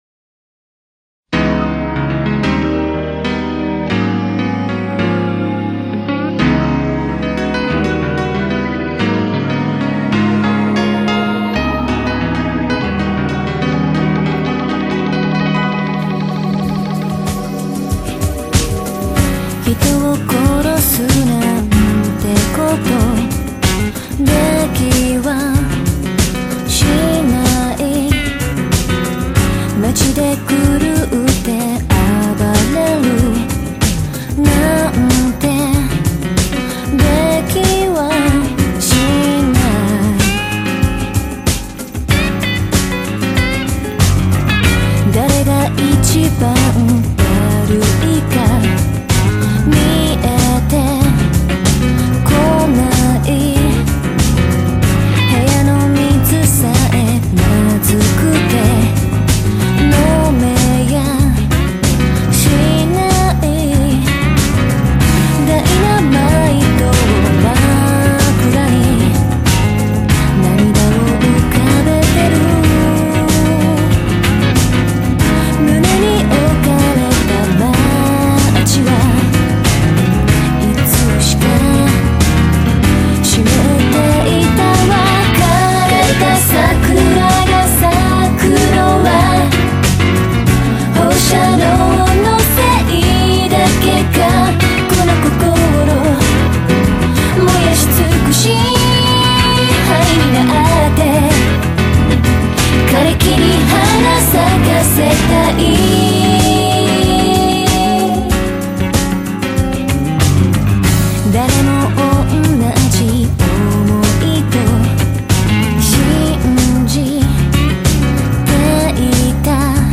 Studios -Studio ampersand, Pioneer Studio, Azabu West Studio